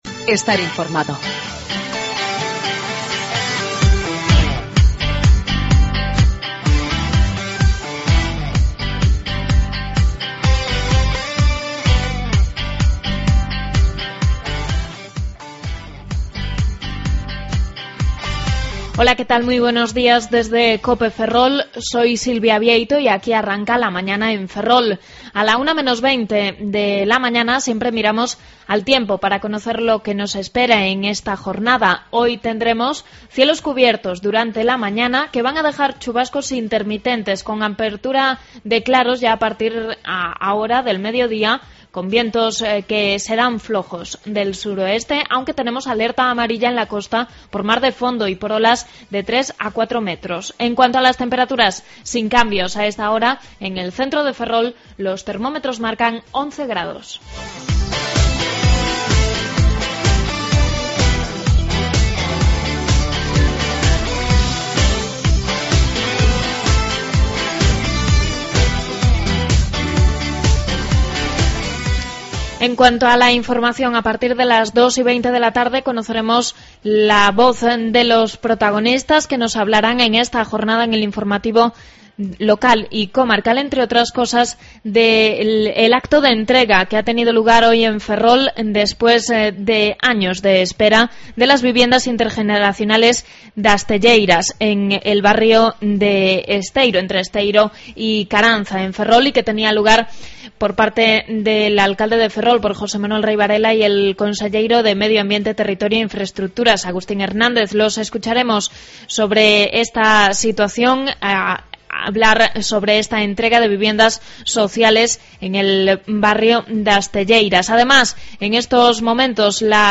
Redacción digital Madrid - Publicado el 02 abr 2013, 14:37 - Actualizado 14 mar 2023, 17:53 1 min lectura Descargar Facebook Twitter Whatsapp Telegram Enviar por email Copiar enlace Avances informativos y contenidos de Ferrol, Eume y Ortegal.